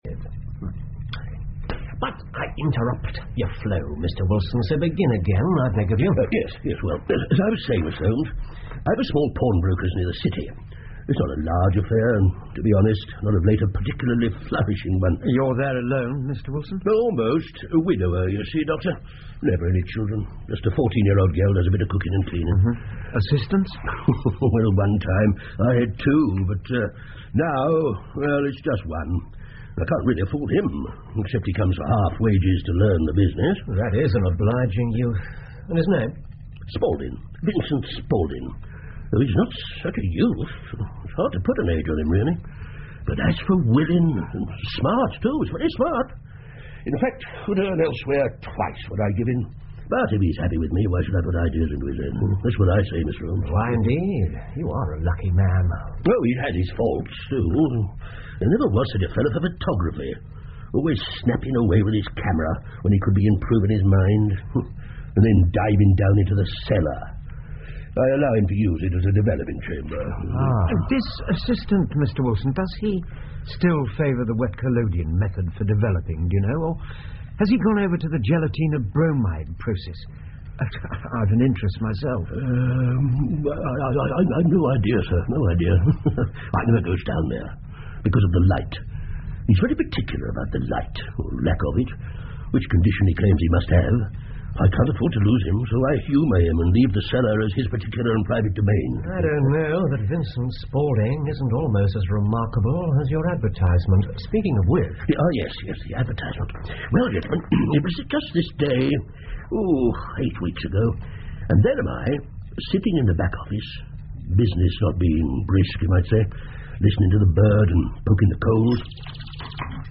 福尔摩斯广播剧 The Red Headed League 2 听力文件下载—在线英语听力室